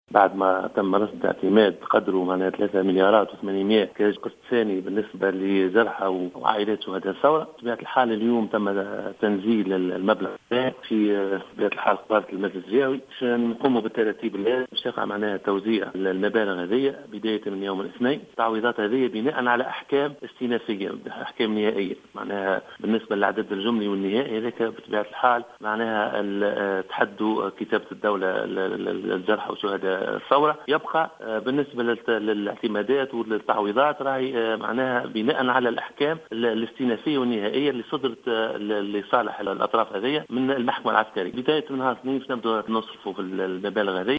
أكد والي القصرين،الشاذلي بوعلاق في تصريح اليوم ل"الجوهرة أف أم" أنه سيتم الشروع في تقديم التعويضات لعائلات شهداء وجرحى الثورة بالقصرين انطلاقا من يوم الاثنين المقبل وذلك بناء على الأحكام النهائية الصادرة لصالحهم من قبل المحكمة العسكرية.